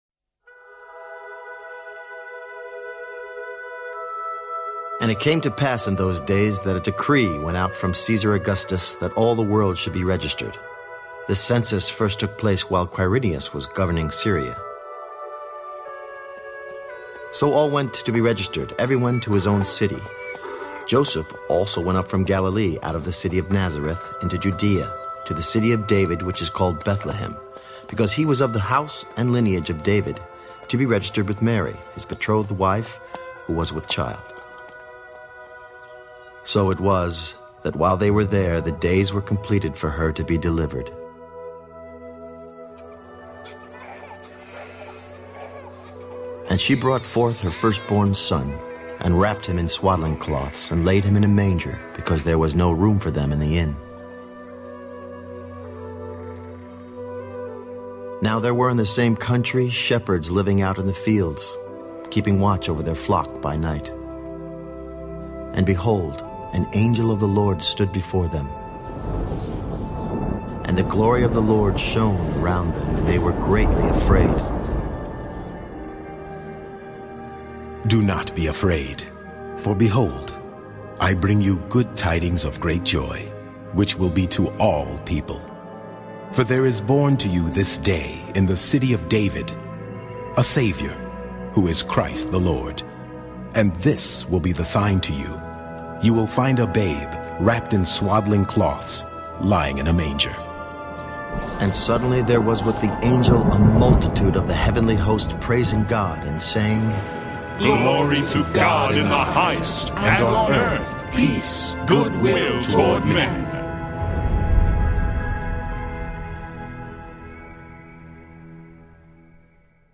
Enjoy Luke from this faithful rendering of the New King James Version (NKJV) Bible in compelling, dramatic audio theater format with world-class audio production. With an original music score by composer Stefano Mainetti (Abba Pater), feature-film quality sound effects, and compelling narration by Michael York and the work of over 500 actors, The Word of Promise Audio Bible will immerse listeners in the dramatic reality of the scriptures as never before. Each beloved book of the Bible comes to life with outstanding performances by Jim Caviezel as Jesus, Richard Dreyfuss as Moses, Gary Sinise as David, Jason Alexander as Joseph, Marisa Tomei as Mary Magdalene, Stacy Keach as Paul, Louis Gossett, Jr. as John, Jon Voight as Abraham, Marcia Gay Harden as Esther, Joan Allen as Deborah, Max von Sydow as Noah, and Malcolm McDowell as Solomon.